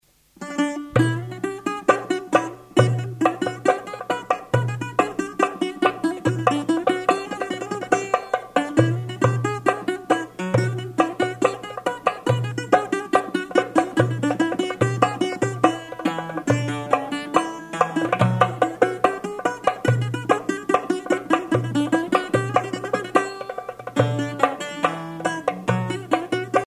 gestuel : danse
Pièce musicale éditée